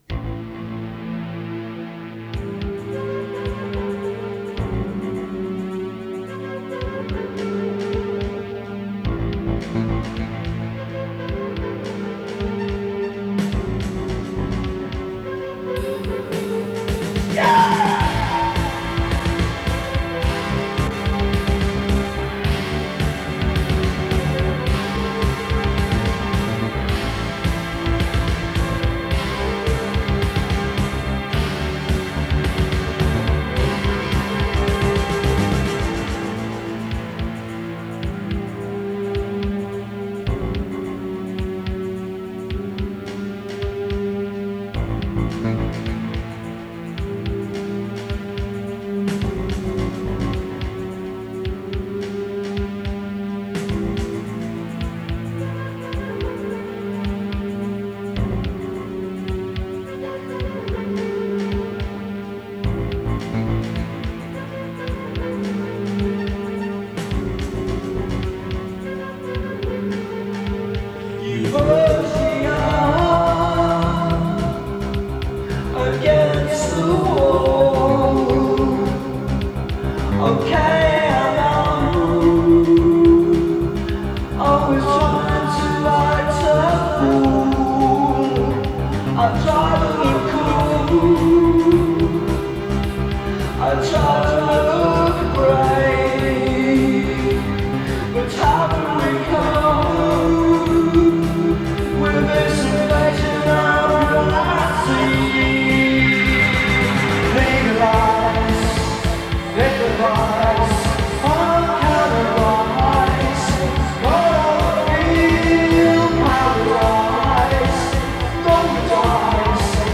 lead vocals, guitar, midi-synth and drum programming
flute, harmony vocals